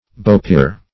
Search Result for " beaupere" : The Collaborative International Dictionary of English v.0.48: Beaupere \Beau"pere`\, n. [F. beau p['e]re; beau fair + p['e]re father.] 1.